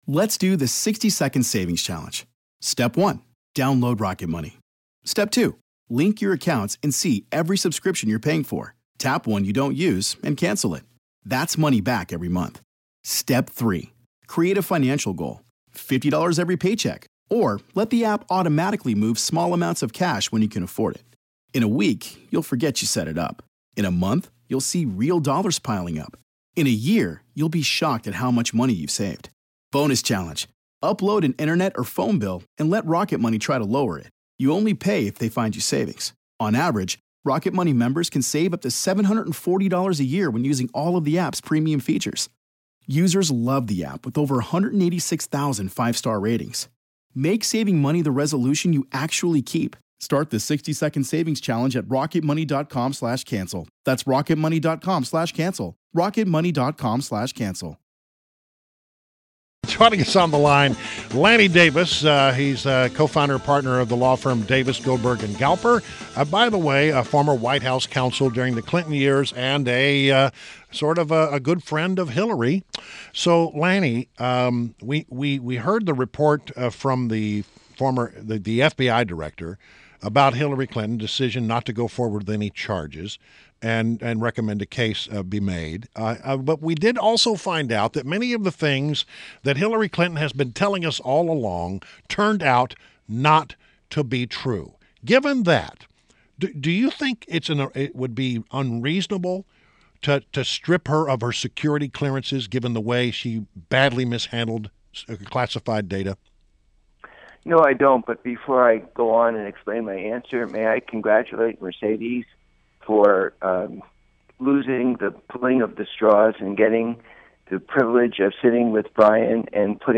WMAl Interview - Lanny Davis - 07.11.16